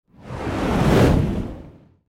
جلوه های صوتی
دانلود صدای آتش 21 از ساعد نیوز با لینک مستقیم و کیفیت بالا
برچسب: دانلود آهنگ های افکت صوتی طبیعت و محیط دانلود آلبوم صدای شعله های آتش از افکت صوتی طبیعت و محیط